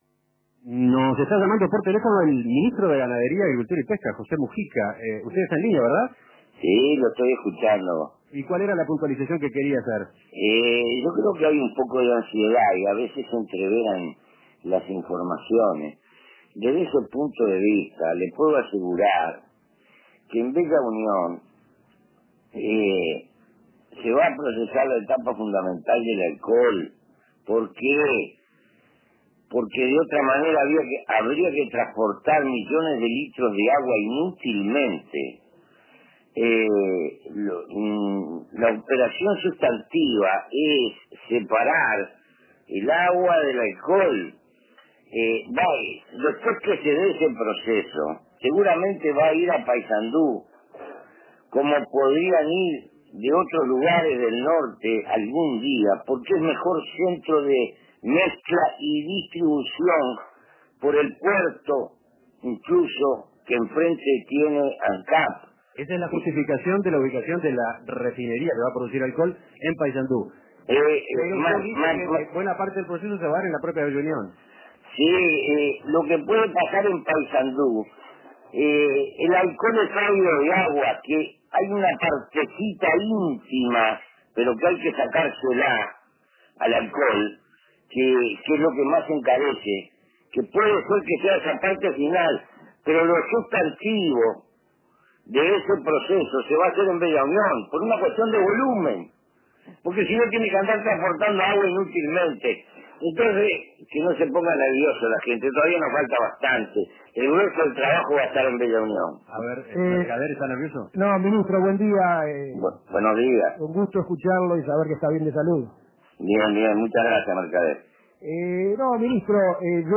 En comunicación telefónica, Mujica aclaró que la parte principal del proceso de producción de alcohol estará en Bella Unión